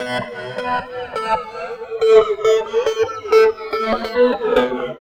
18 GUIT 1 -L.wav